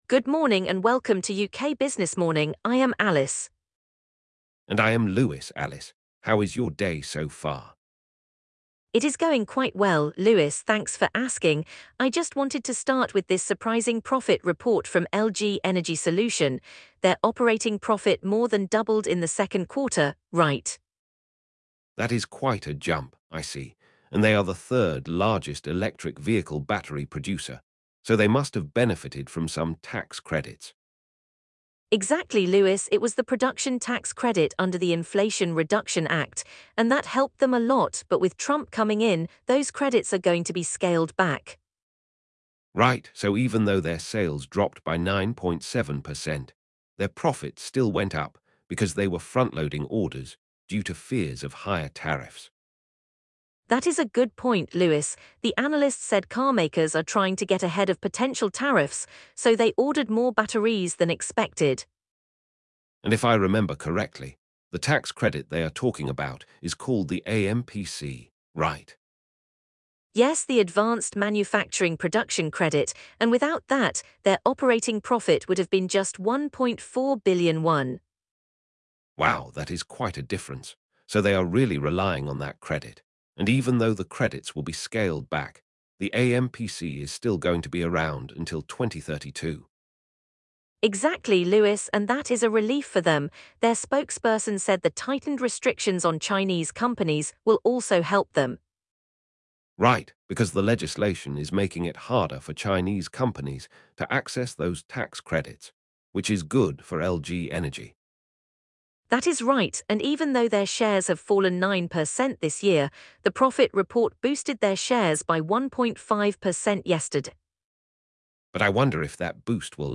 With Trump's potential scaling back of these credits, the hosts analyze the challenges and opportunities for LG Energy Solution, the third-largest EV battery producer. They also touch on the slowing EV demand growth, competition from Chinese firms like CATL, and the significance of the AMPC's extension until 2032.